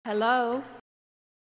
hello-melania-trump-saying